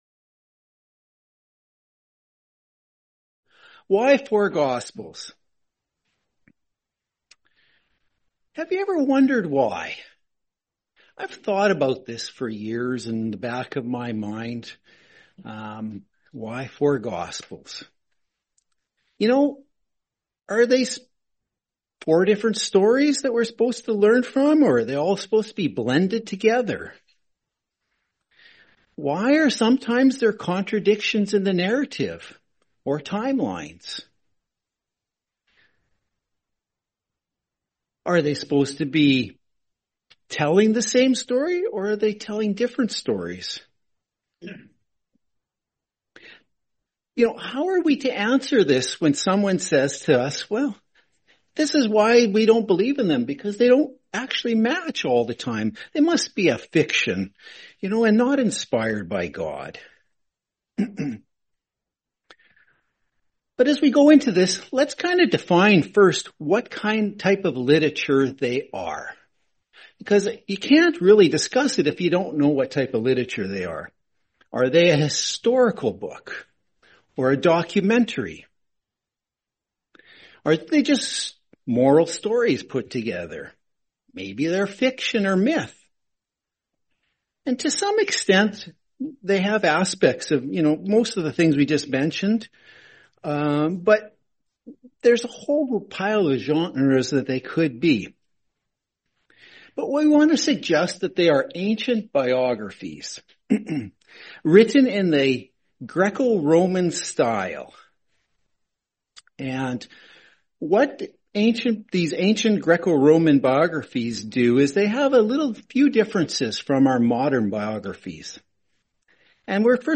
Series: 2025 Sacramento Convention